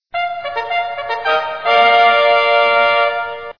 Fanfare.wav